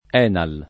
vai all'elenco alfabetico delle voci ingrandisci il carattere 100% rimpicciolisci il carattere stampa invia tramite posta elettronica codividi su Facebook ENAL [ $ nal ] n. pr. m. — sigla di Ente Nazionale Assistenza Lavoratori